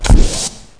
1 channel
tick1.mp3